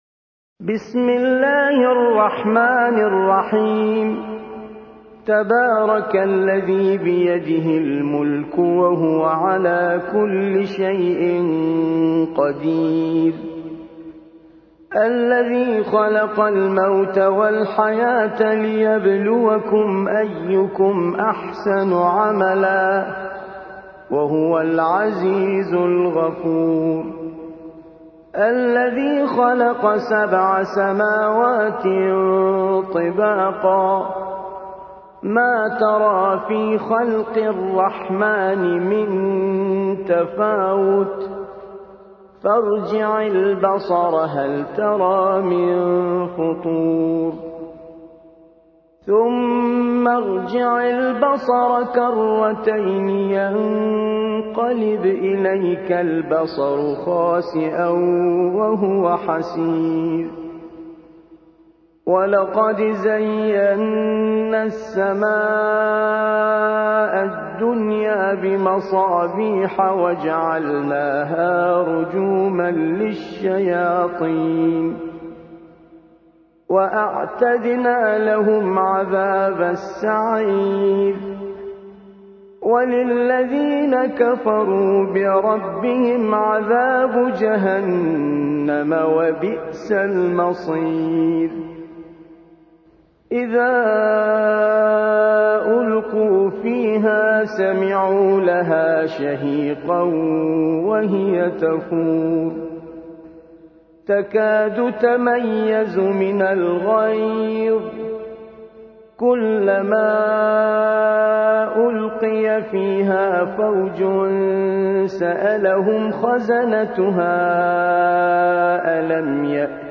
67. سورة الملك / القارئ